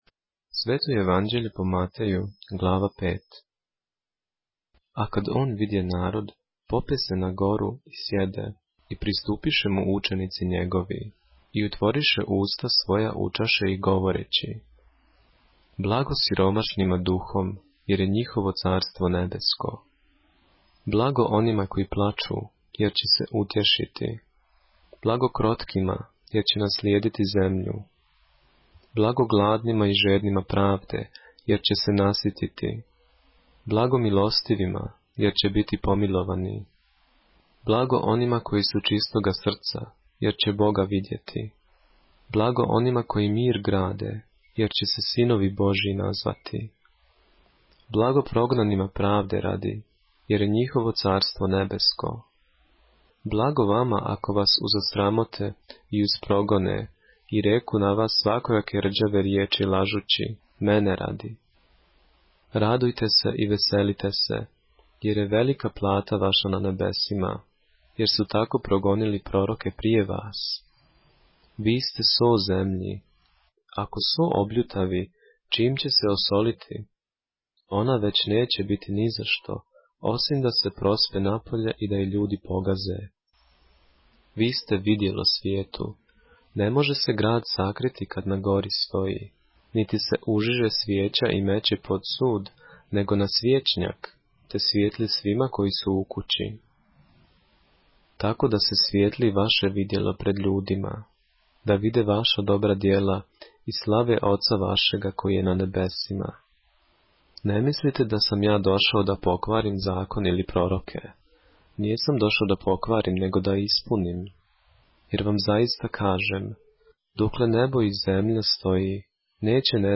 поглавље српске Библије - са аудио нарације - Matthew, chapter 5 of the Holy Bible in the Serbian language